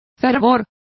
Complete with pronunciation of the translation of fervor.